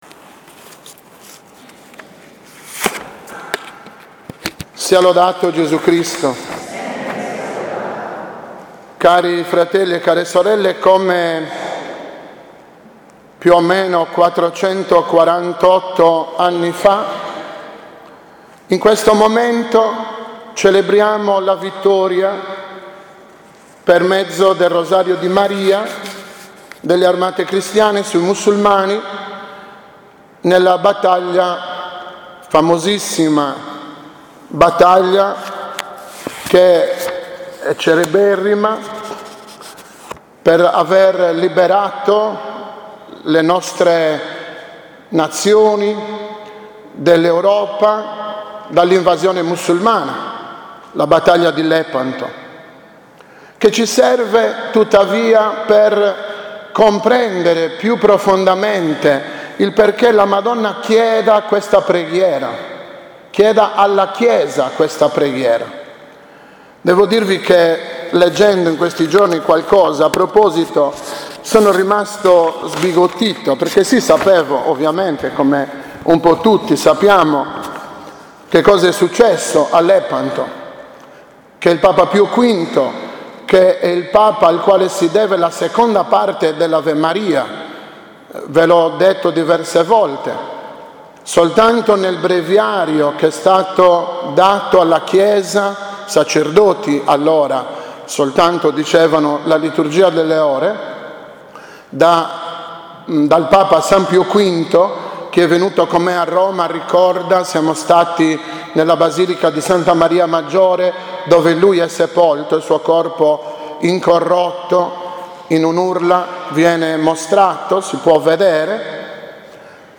2019-OMELIA-NELLA-FESTA-DI-N.-S.-DEL-ROSARIO.mp3